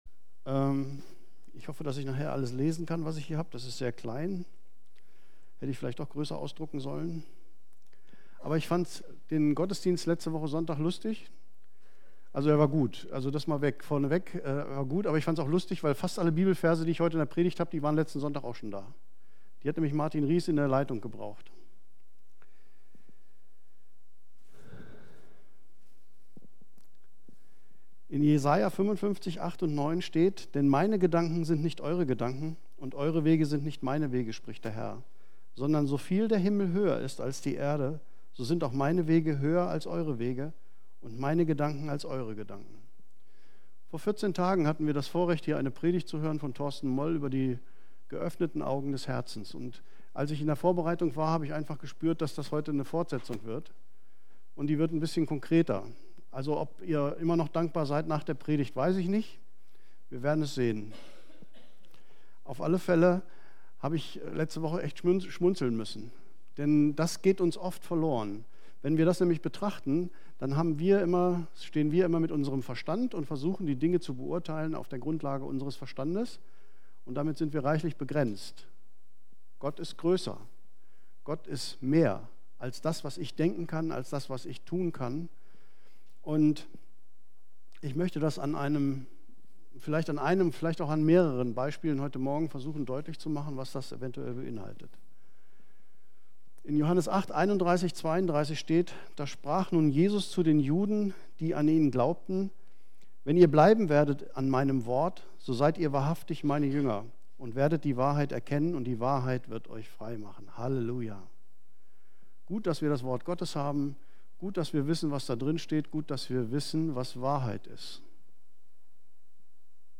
Kirche am Ostbahnhof, Am Ostbahnhof 1, 38678 Clausthal-Zellerfeld, Mitglied im Bund Freikirchlicher Pfingstgemeinden KdöR
Predigten